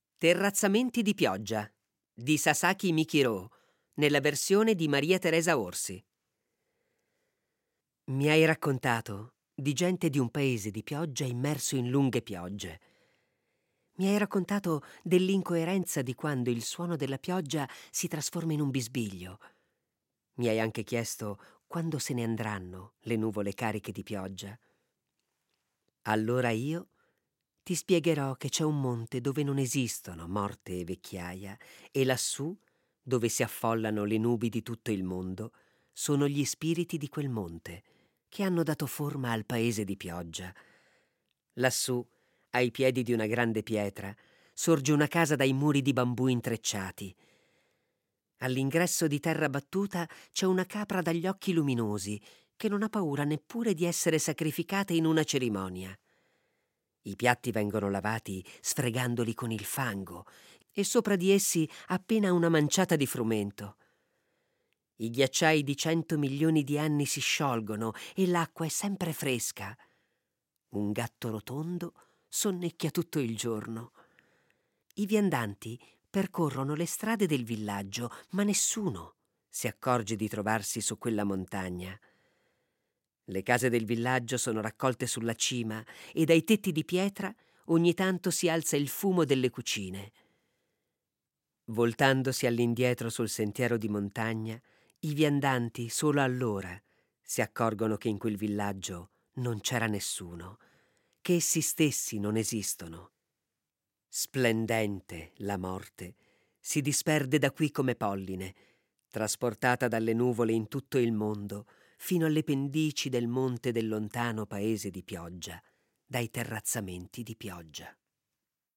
Colpo di poesia dà loro voce per alcune settimane attraverso le letture